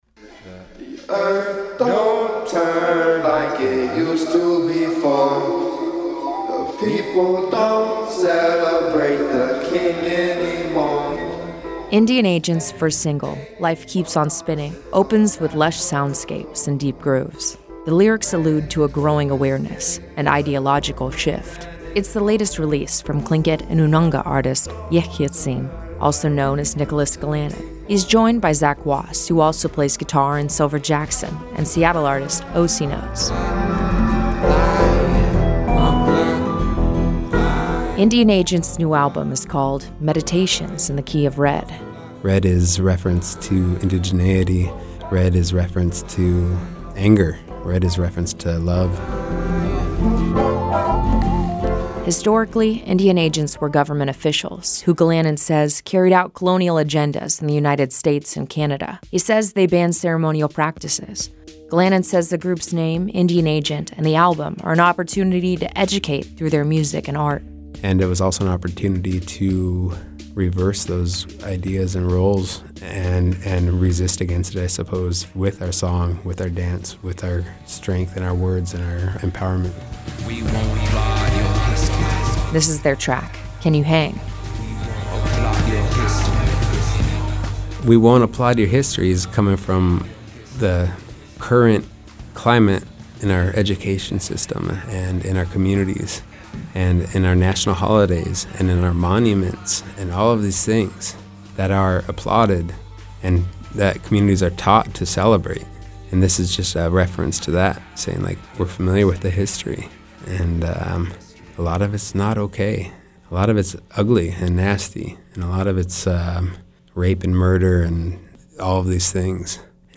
Indian Agent’s first single “Life keeps on spinning” opens with lush soundscapes and deep grooves.